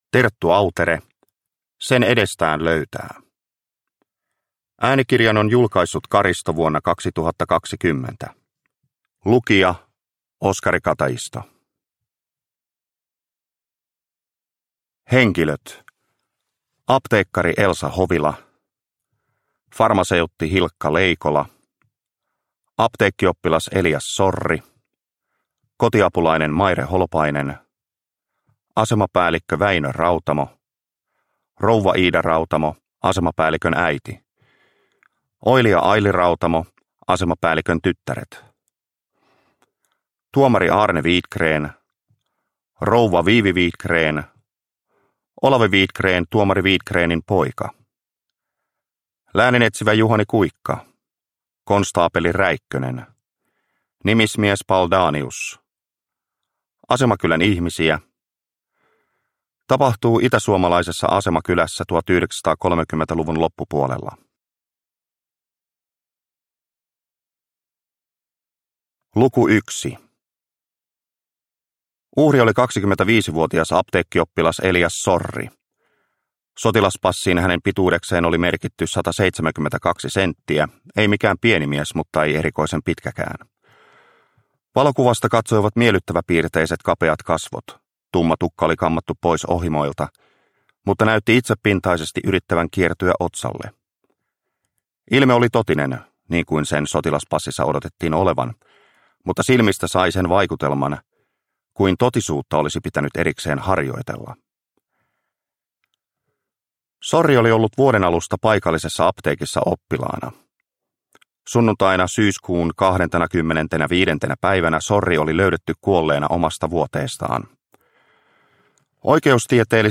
Sen edestään löytää – Ljudbok – Laddas ner